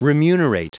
Prononciation du mot remunerate en anglais (fichier audio)
Prononciation du mot : remunerate